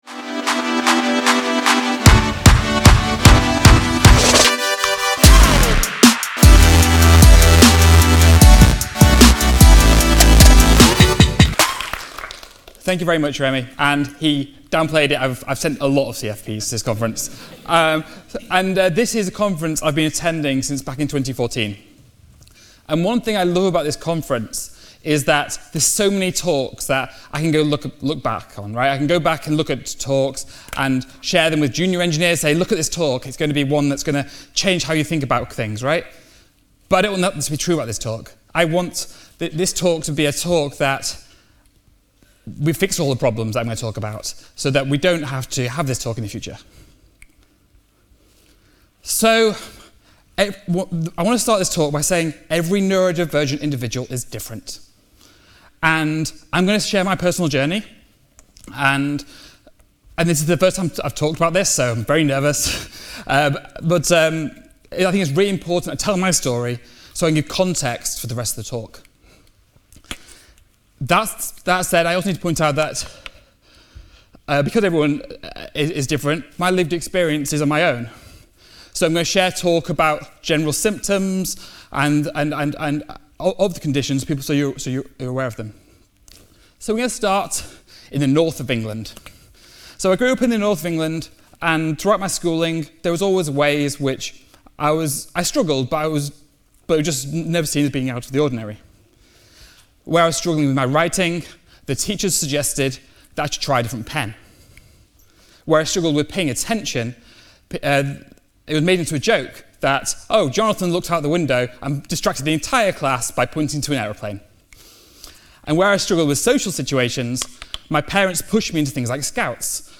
Neurodiversity in Tech: FFConf 2023